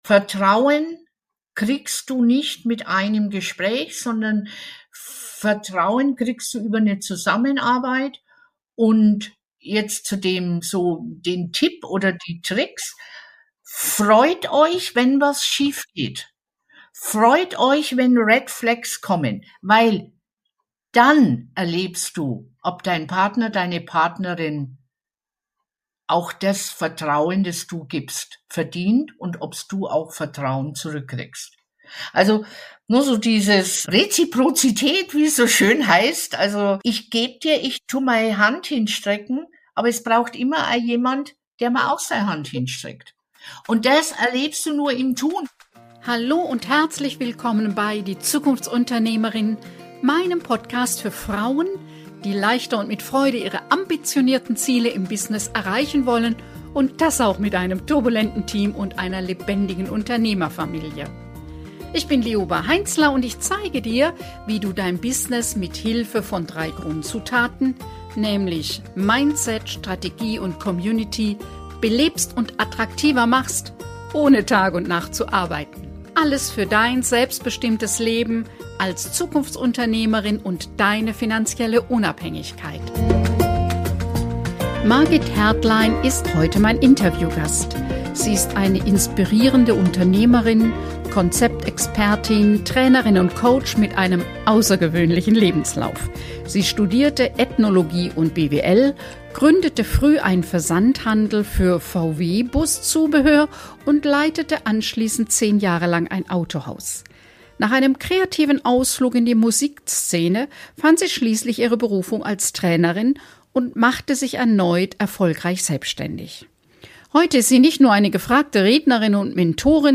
Interviewgast